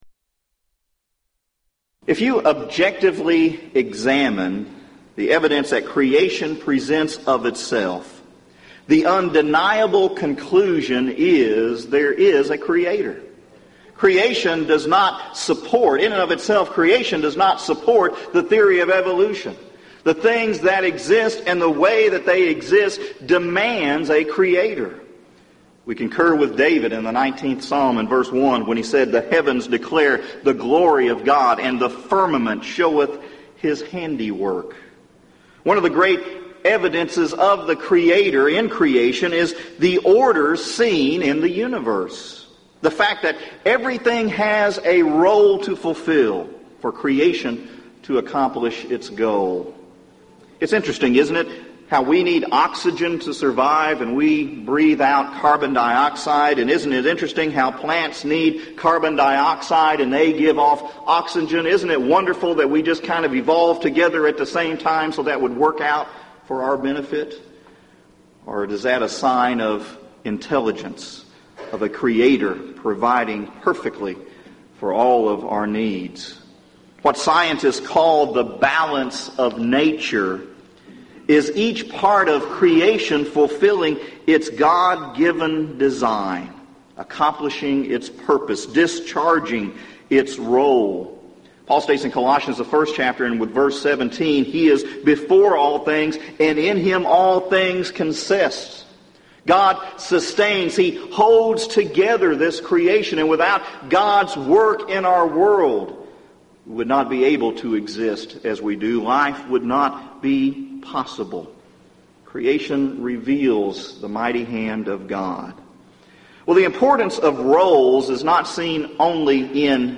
Event: 1998 Gulf Coast Lectures
lecture